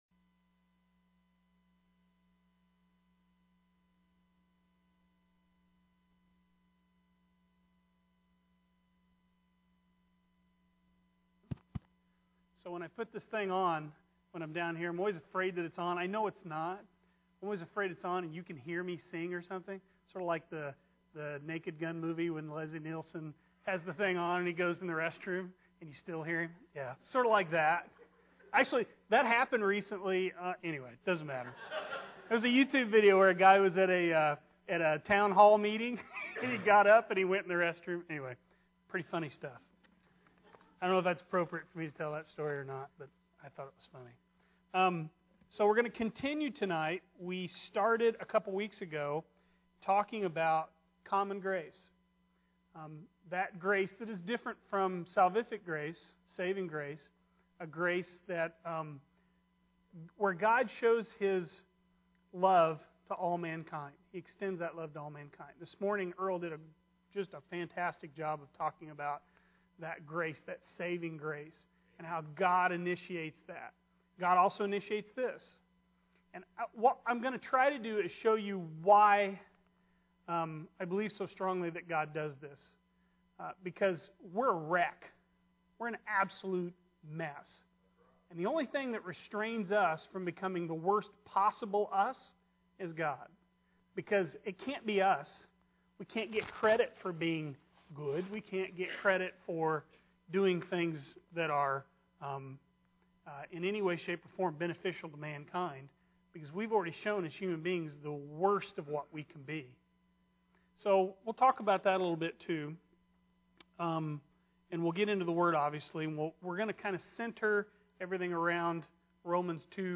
Sermons Archive - Page 32 of 36 - East Side Baptist Church